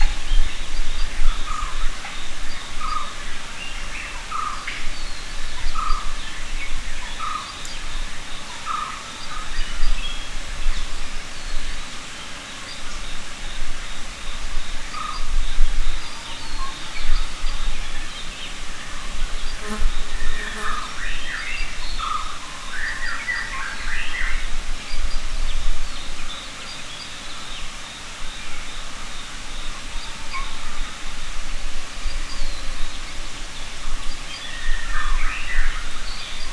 mandrake foundry13data/Data/modules/soundfxlibrary/Nature/Loops/Forest Day
forest-day-4.mp3